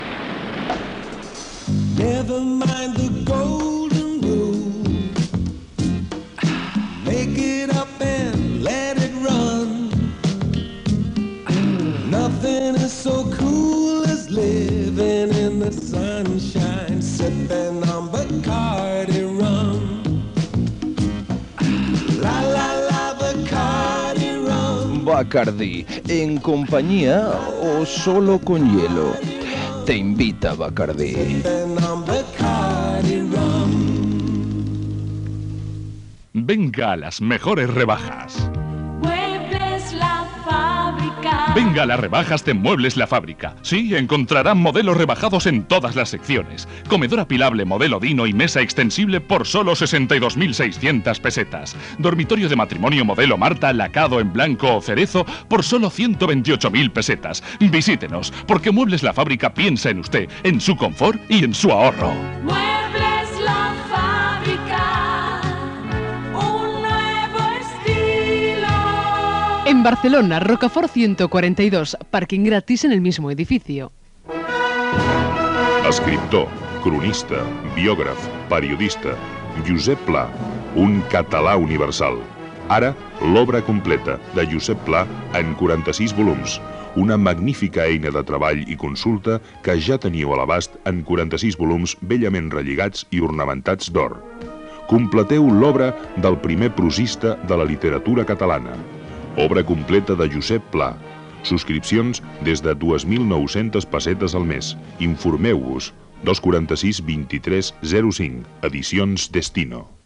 Bloc publicitari